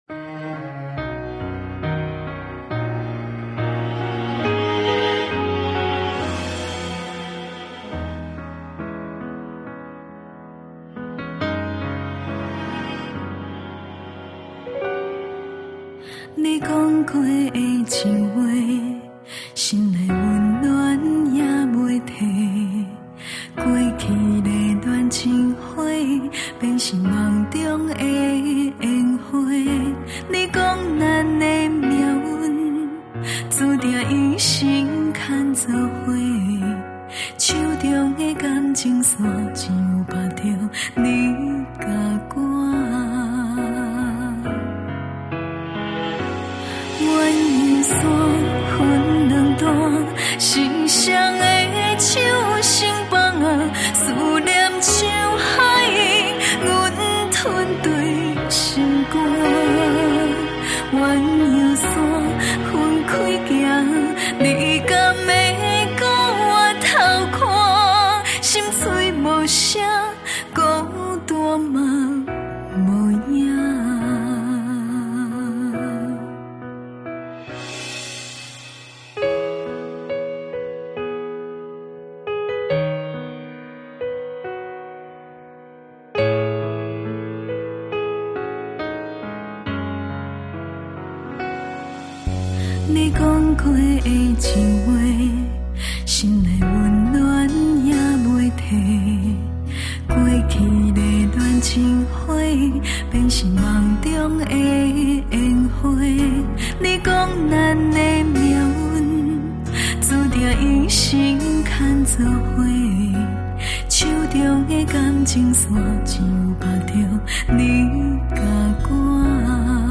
華語台語